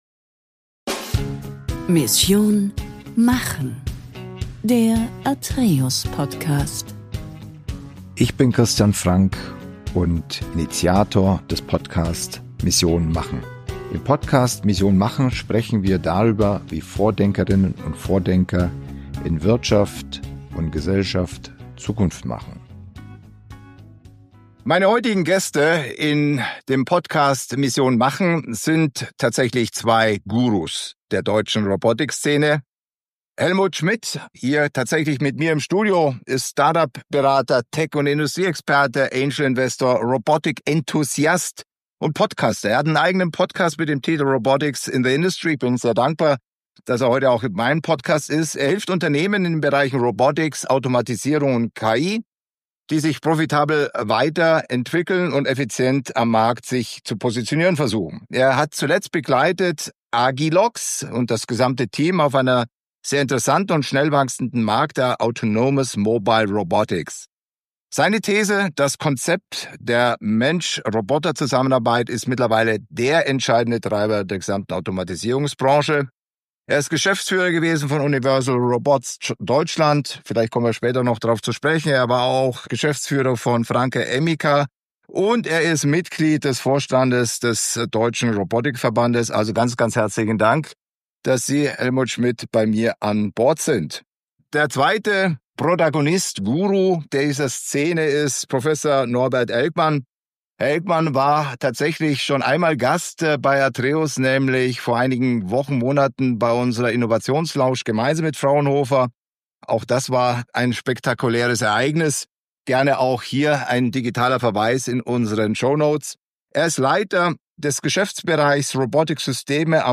Zwei führende Stimmen der Robotik in Deutschland reden Klartext: Wo Forschung glänzt, die Industrie strauchelt – und welche KI‑Hebel jetzt wirklich in die Fabrik kommen.